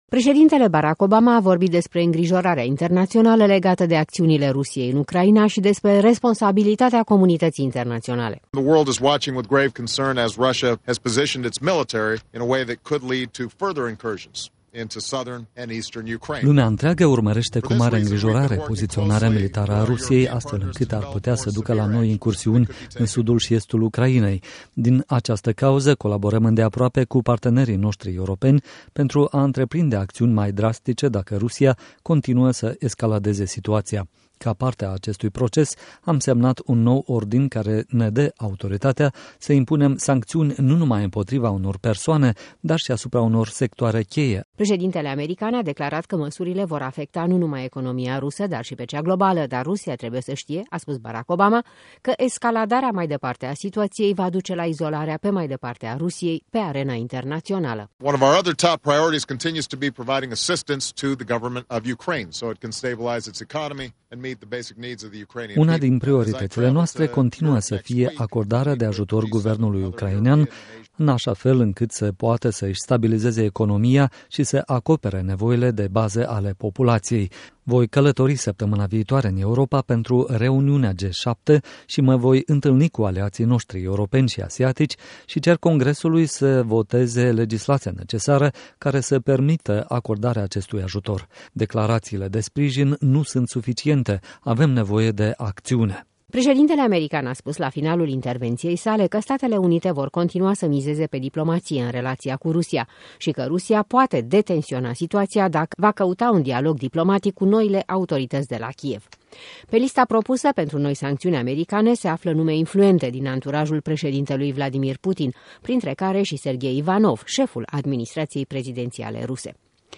Corespondența zilei de la Bruxelles